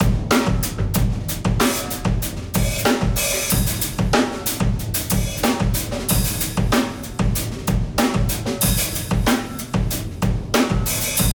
Room Mic Comparison
Late night tracking session for upcoming compilation, Set up three different stereo room fill mics.
They all sound great – the PZM for a rounder old-skool feel, the 414 or MD1 for a bit more punch!